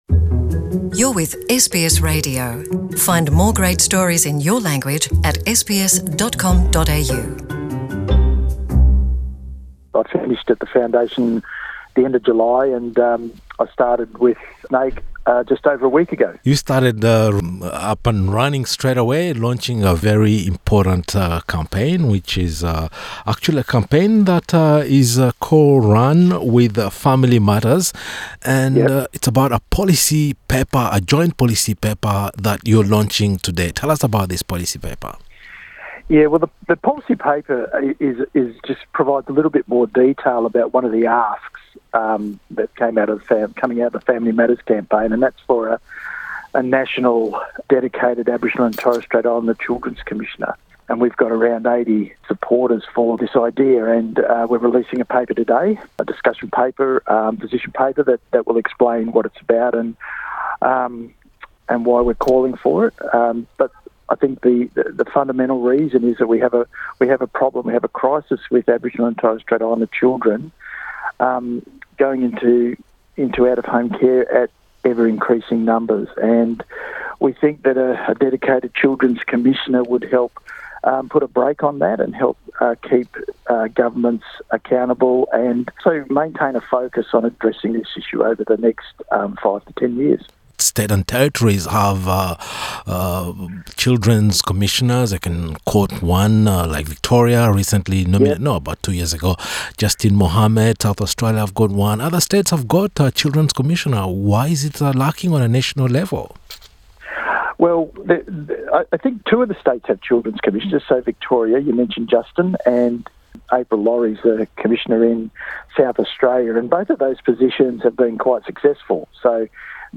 SBS NITV Radio